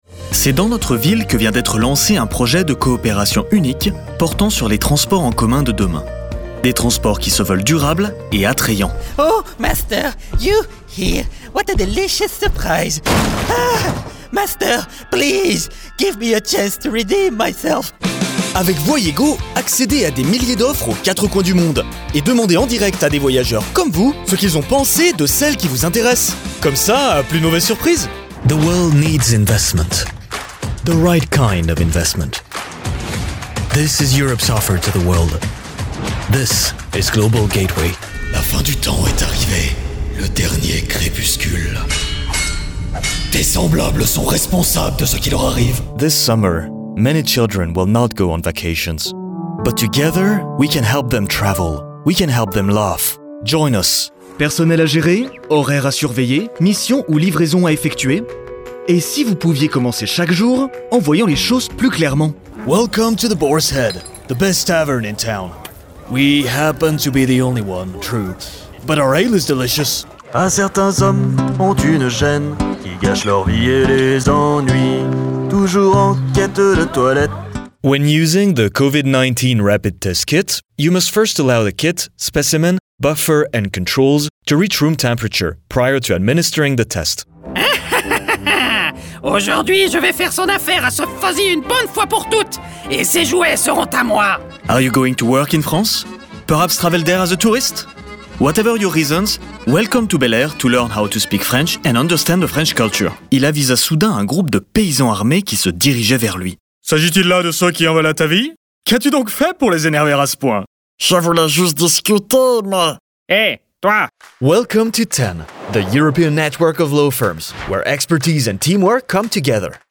Looking to hire French voice over artists?
Female
Assured , Authoritative , Bright , Bubbly , Character , Children , Confident , Cool , Corporate , Deep , Engaging , Friendly , Natural , Posh , Reassuring , Soft , Versatile